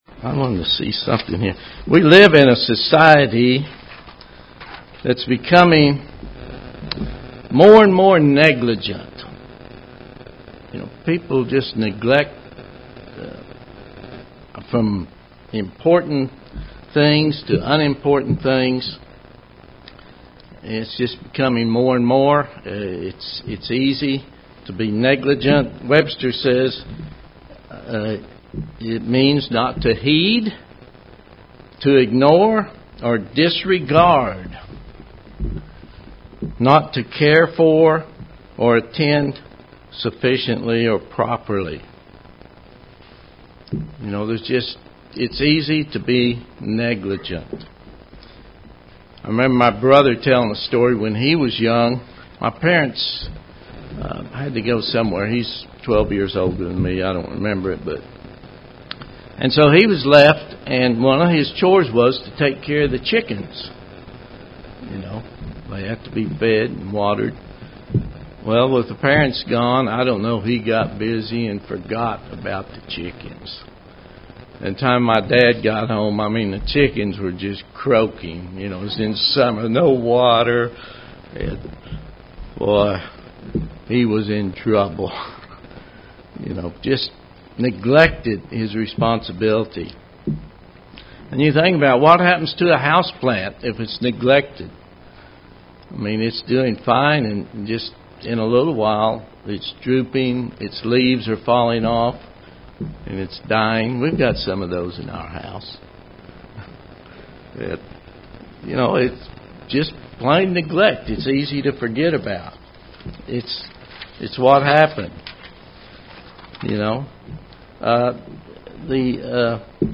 Given in Oklahoma City, OK
UCG Sermon Studying the bible?